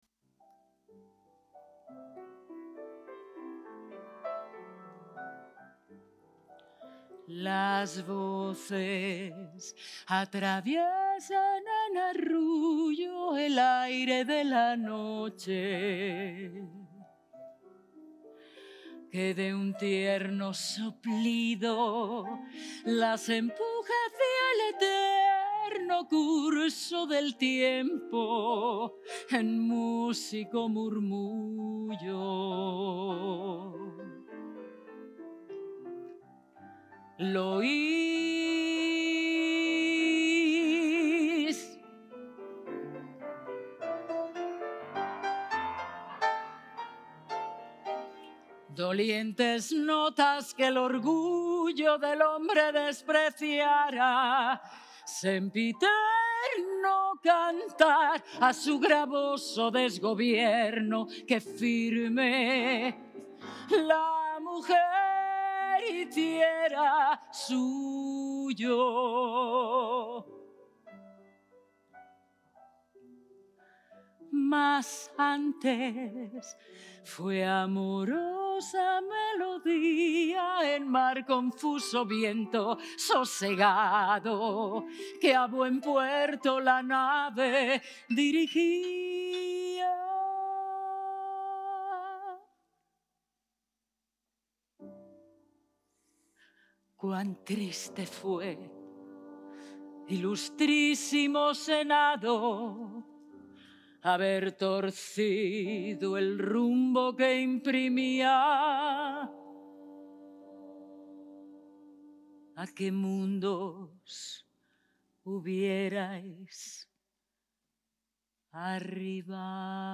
Instrumentación: Tenor/soprano y piano.